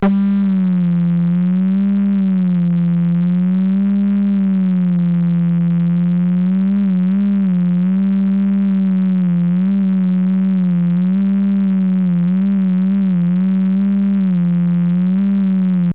Plus tard, les données étaient lues au sol et on avait immédiatement un aperçu des résultats en connectant un haut parleur en sortie de notre enregistreur (les données étant en fait restituées en fréquences sonores).